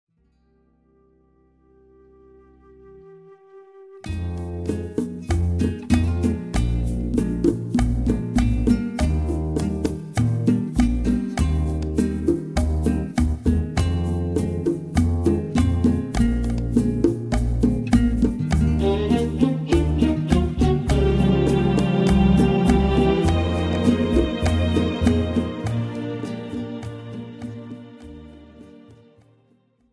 This is an instrumental backing track cover.
• Key – E♭
• Without Backing Vocals
• No Fade